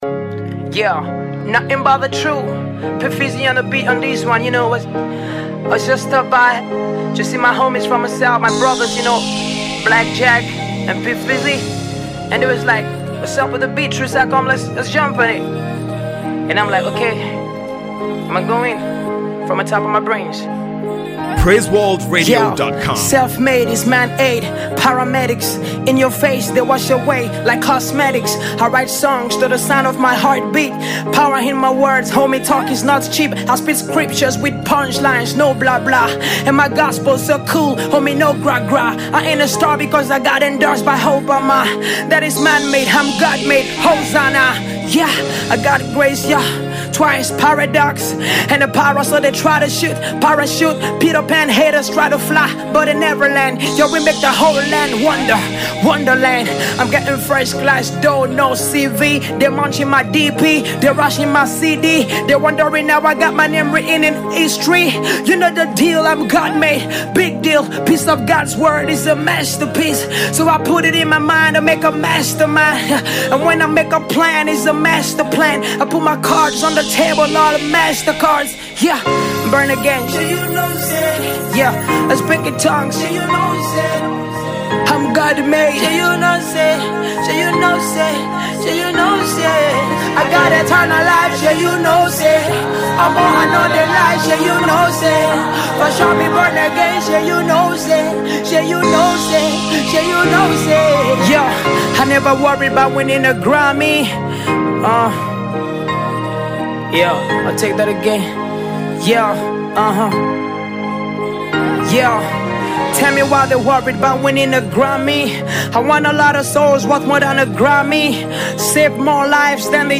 is a Gospel Music Minister
passionately delivered masterpiece.